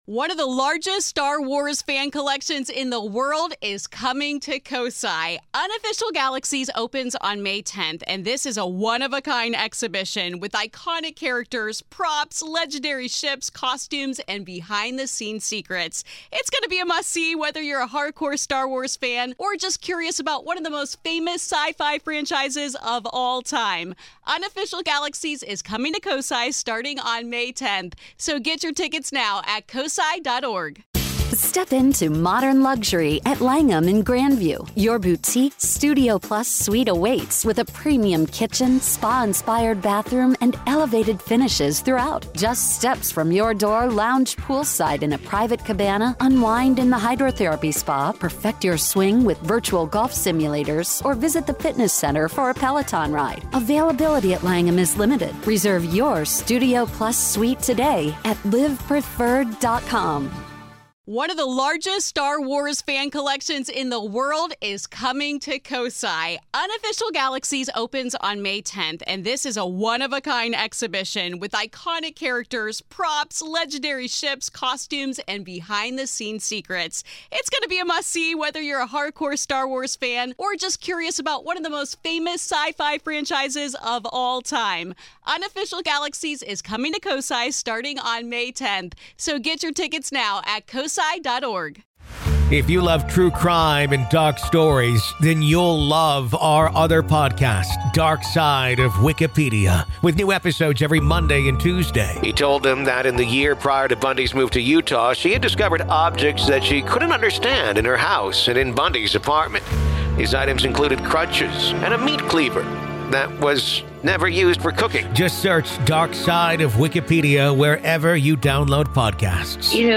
Targeted By Spirits | A Conversation